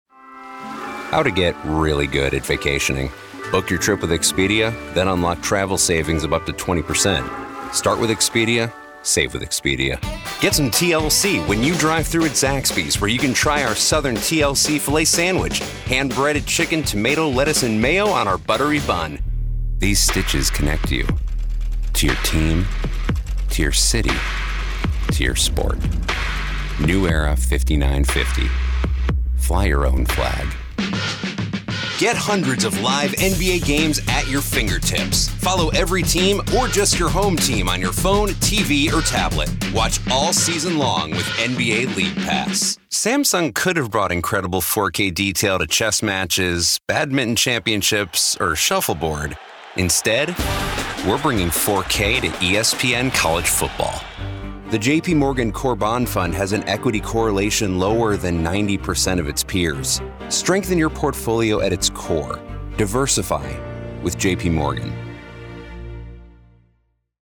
Mature Adult, Adult
Location: Atlanta, GA, USA Languages: english 123 Accents: standard us Voice Filters: VOICEOVER GENRE commercial